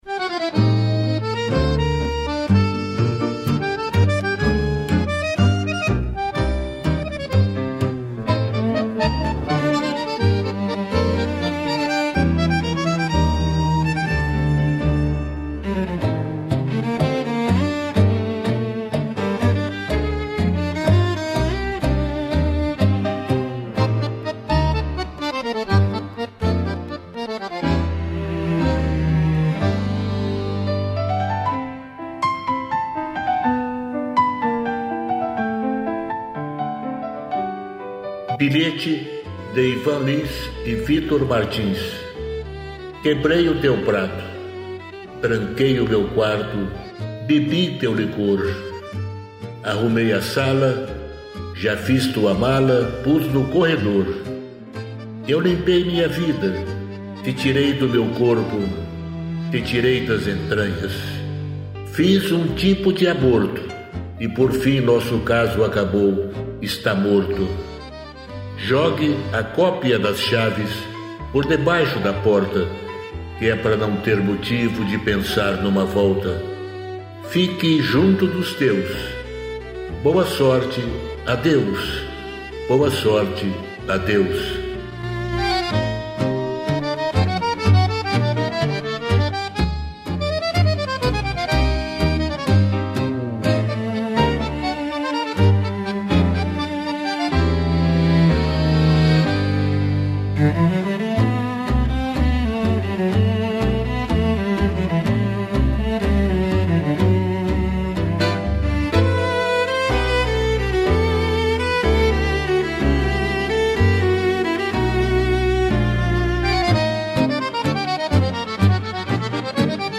musica e arranjo: IA